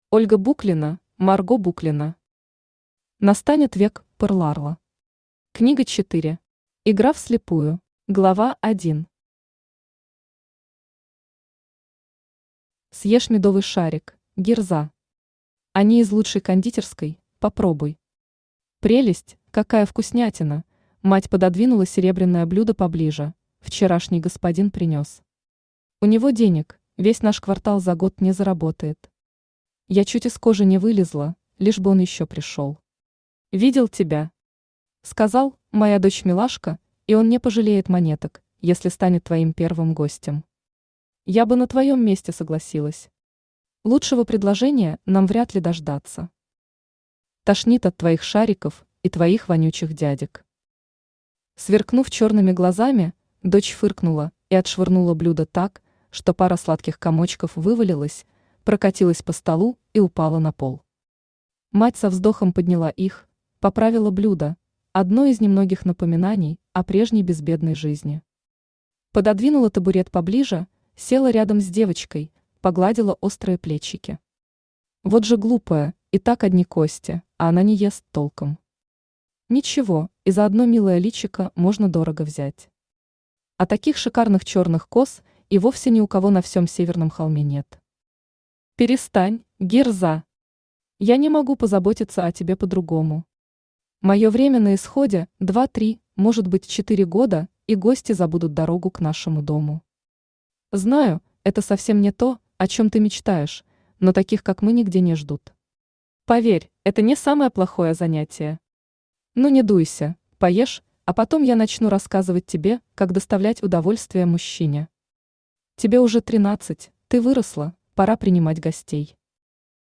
Игра вслепую Автор Ольга Буклина Читает аудиокнигу Авточтец ЛитРес.